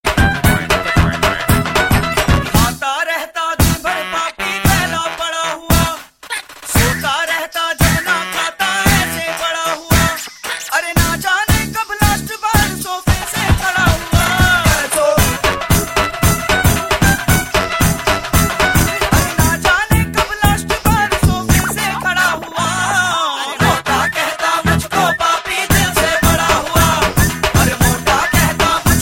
File Type : Bollywood ringtones